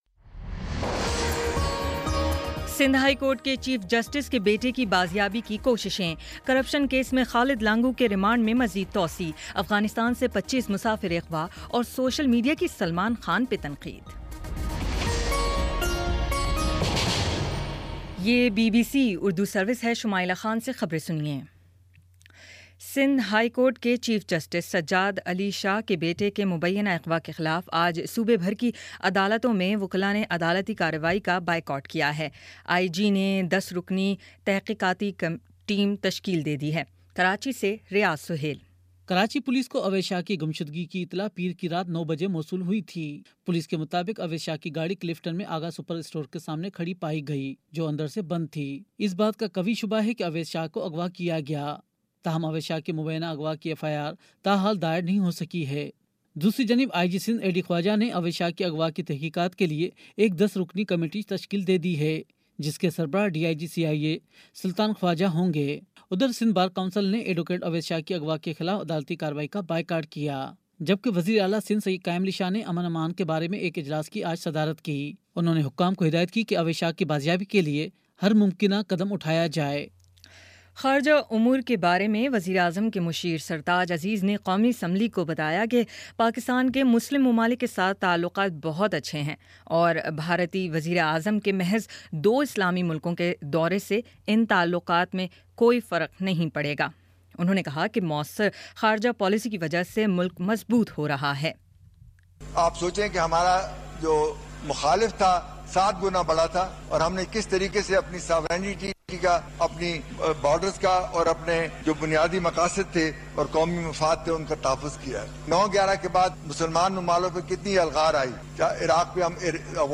جون 21 : شام پانچ بجے کا نیوز بُلیٹن